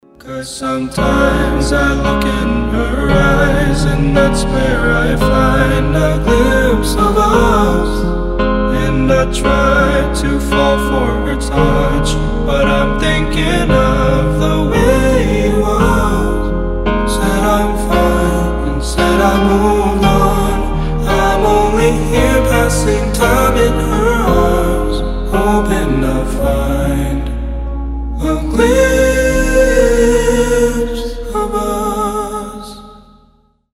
красивые
красивый мужской голос
пианино
нежные
баллады